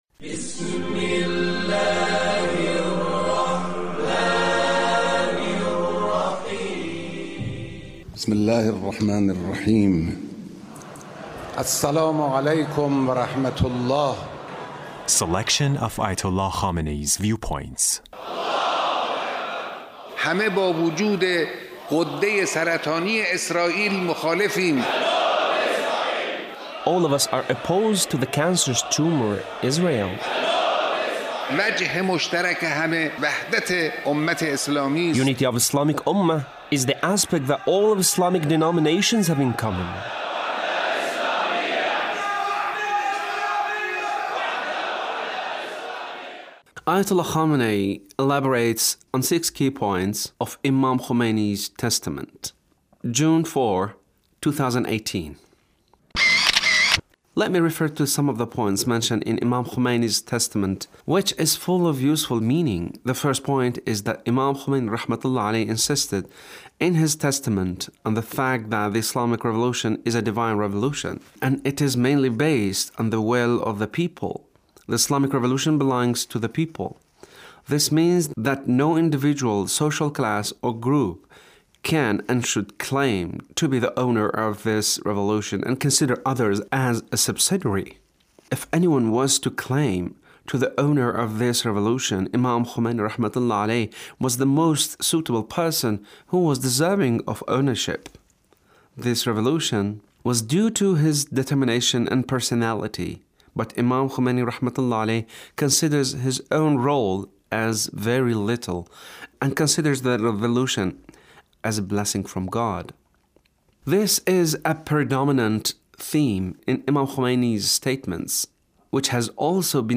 The Leader's speech on Quds Day